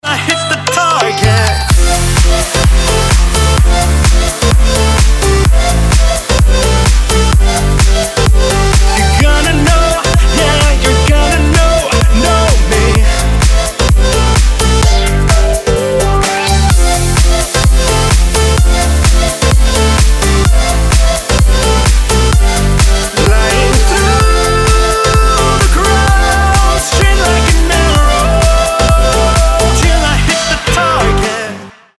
• Качество: 320, Stereo
dance
Club House
Танцевальный летний клубный хит